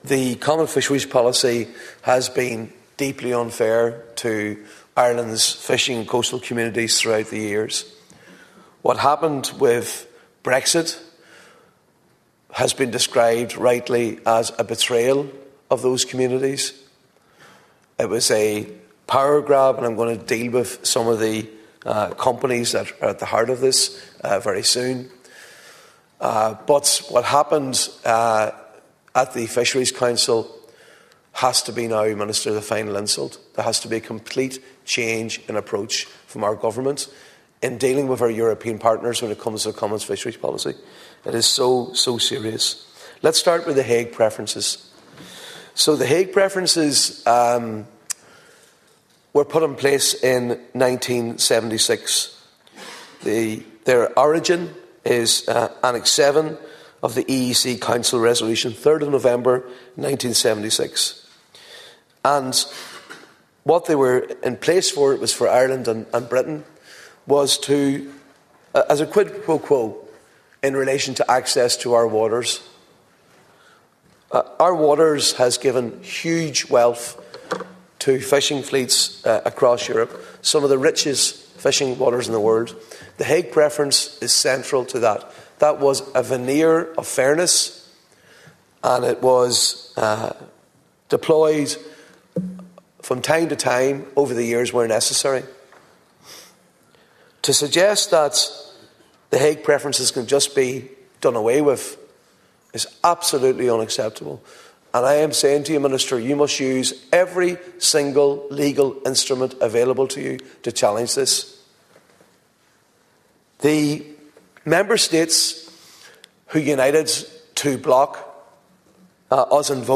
In the Dail, Deputy Padraig MacLochlainn said the actions of Germany, France, Poland and the Netherlands amounted to a betrayal of Ireland, and jeapordise the future of the fishing sector here.
You can hear the whole of Deputy MacLochainn’s speech here –